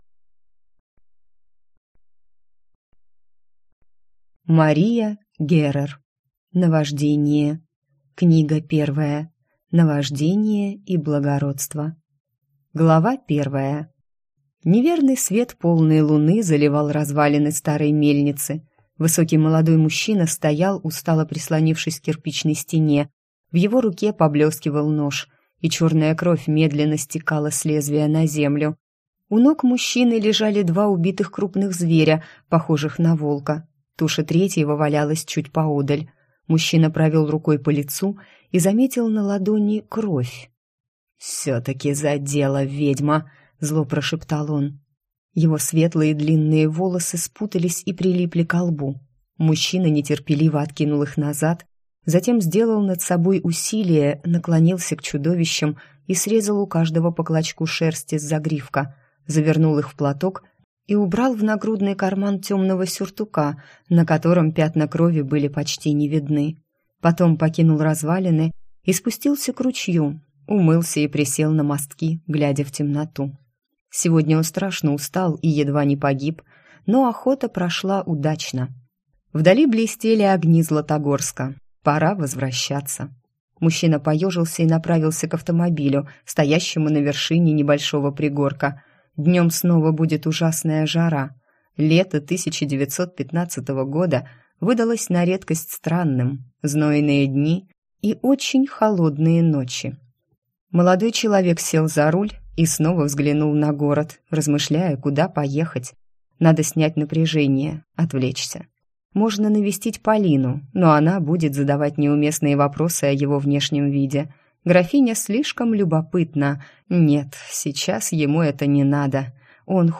Аудиокнига Наваждение. Книга 1. Наваждение и благородство | Библиотека аудиокниг